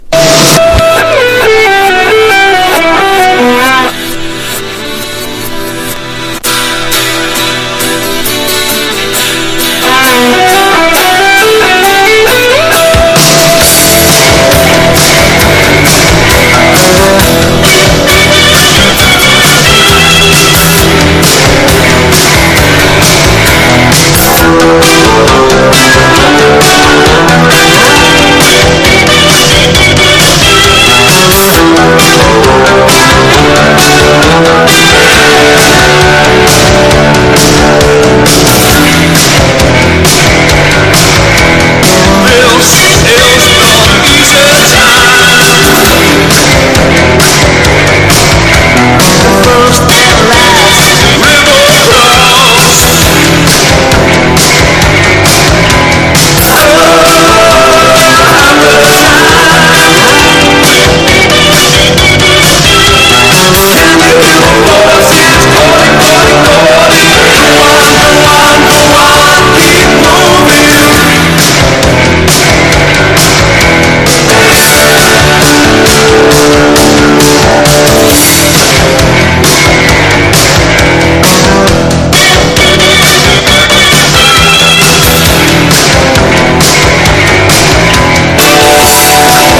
ALTERNATIVE / GRUNGE# NEW WAVE# POST PUNK